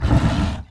monster2 / fire_tiger / walk_1.wav
walk_1.wav